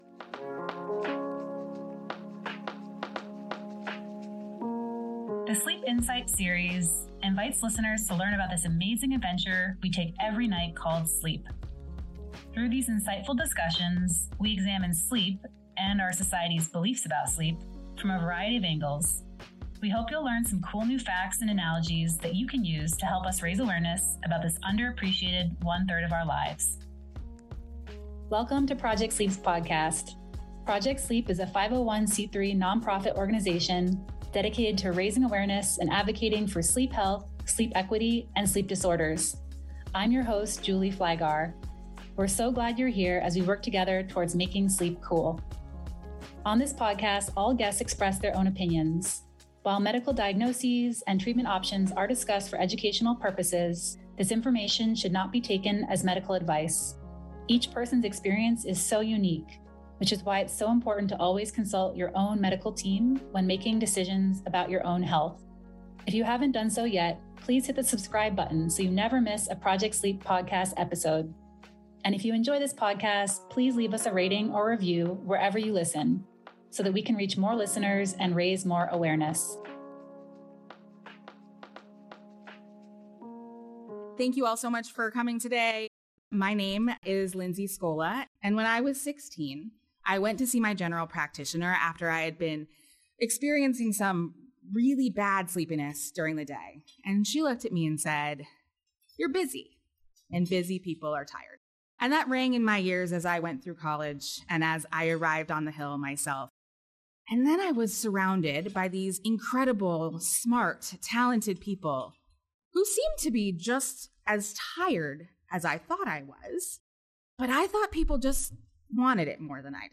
Congressional Briefing on Sleep & Public Health - Sleep Insights Series Ep 13